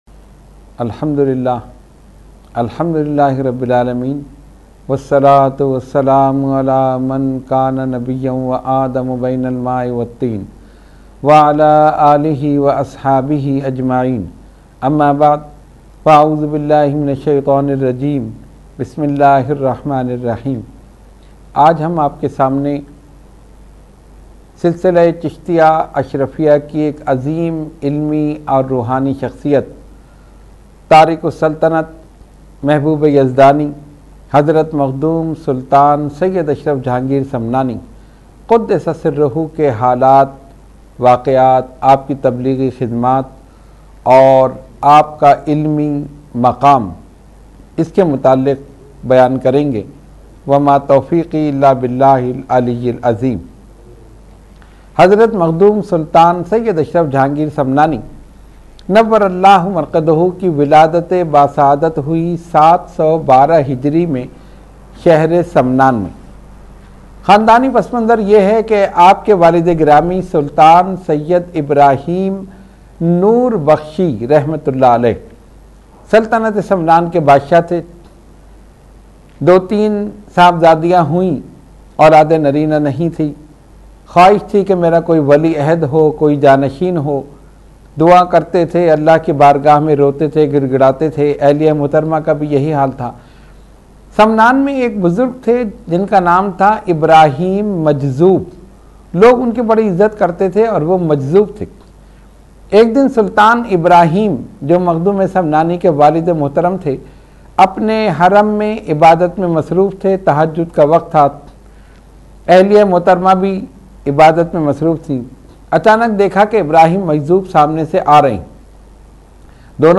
Roohani Tarbiyati Nashist held at Dargah Aliya Ashrafia Ashrafia Ashrafabad Firdous Colony Gulbahar Karachi.
Category : Speech | Language : UrduEvent : Weekly Tarbiyati Nashist